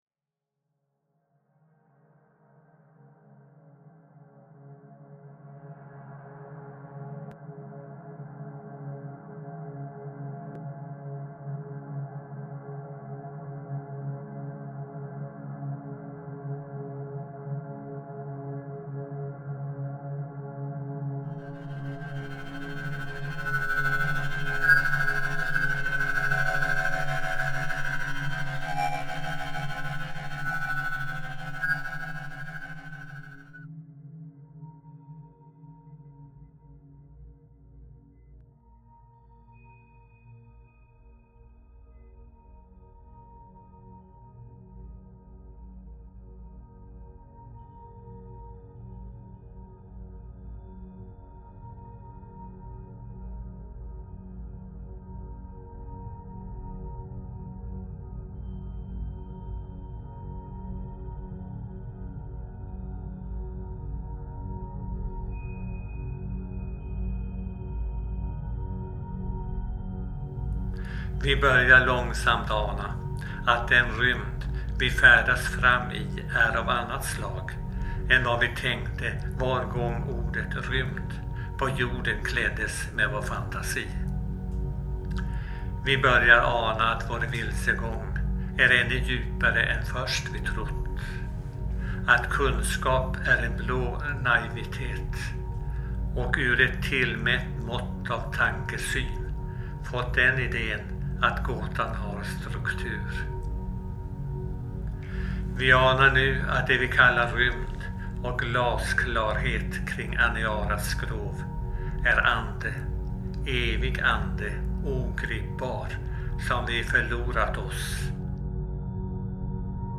sound and spoken word
The text is read by former bishop Martin Lönnebo, the complete sound composition ( 30 minutes ) was finished in 2009 / 2010.